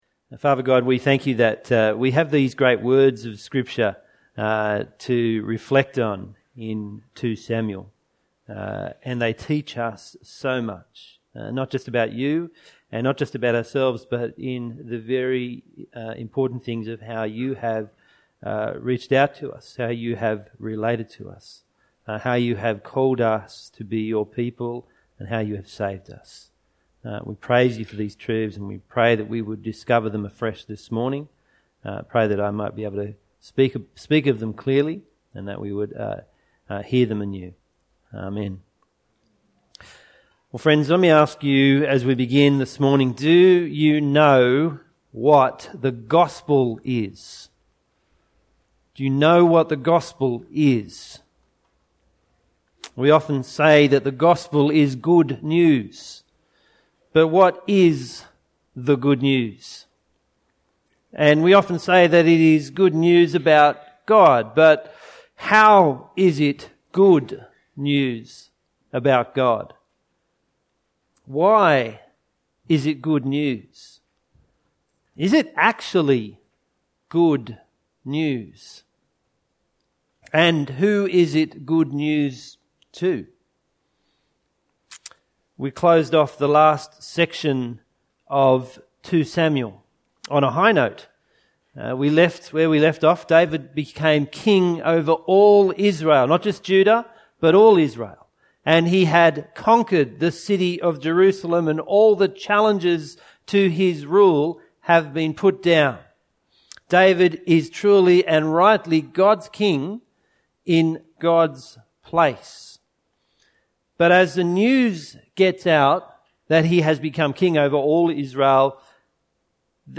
Sermon – The Sceptre Comes to Judah (2 Sam 7:1-29)